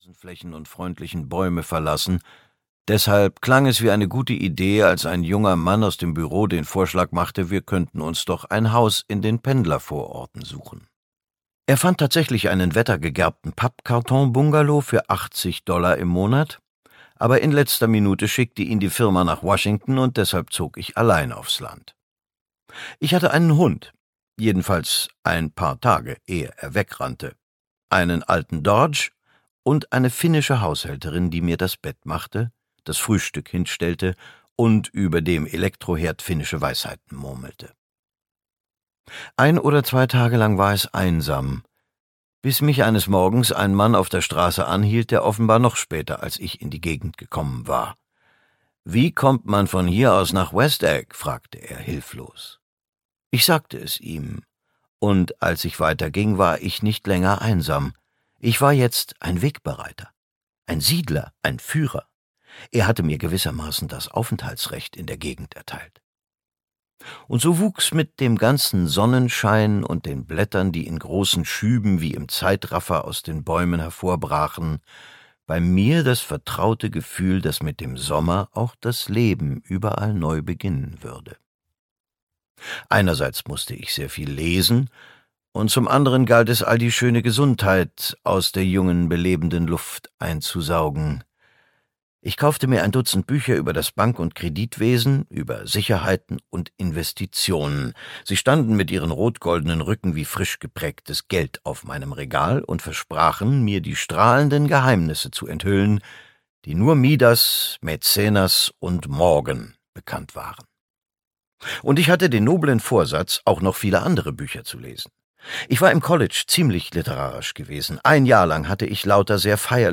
Der große Gatsby (DE) audiokniha
Ukázka z knihy
• InterpretBurghart Klaußner